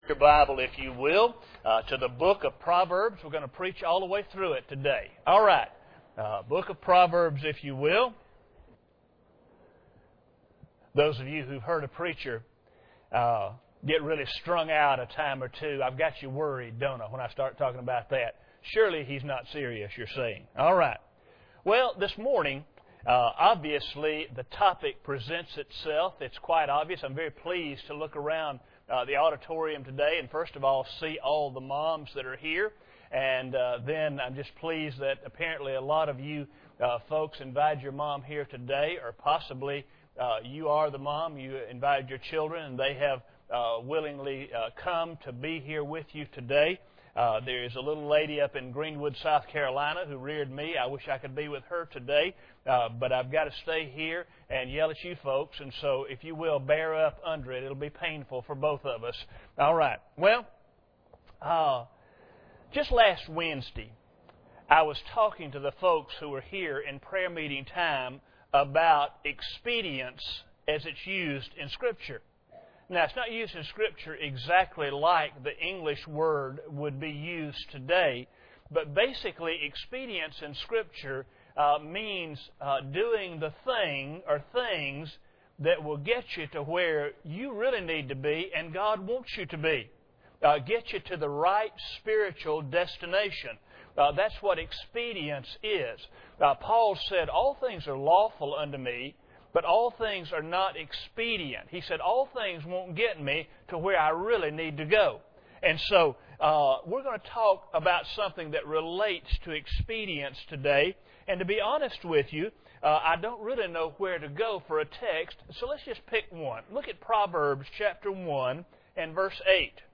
General Service Type: Sunday Morning Preacher